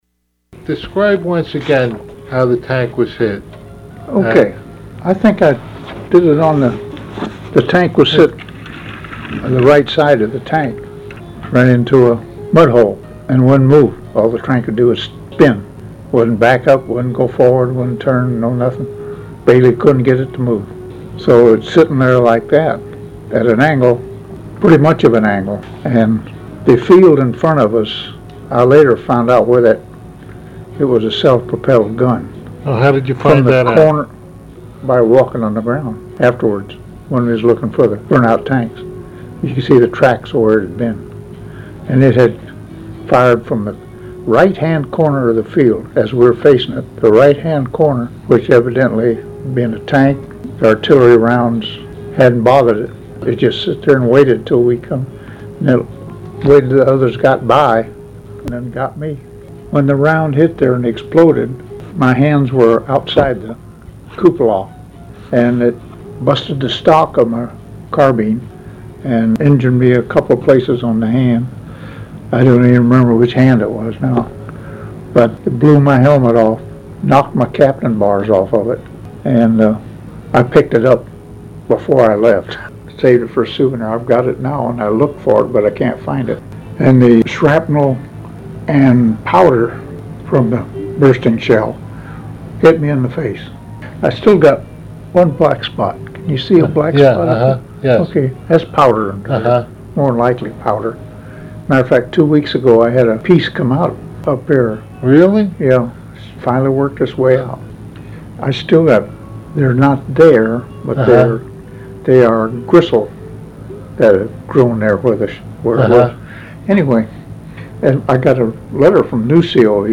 Also, he showed me photographs and described them.
The interview spanned two days, and filled five 90-minute audiocassettes and about 20 minutes of a sixth.